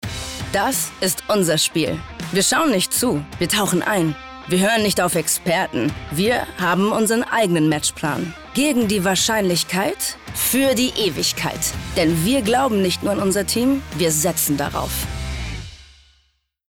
dunkel, sonor, souverän, markant
Mittel minus (25-45)
Norddeutsch
Sportwetten Werbung
Commercial (Werbung)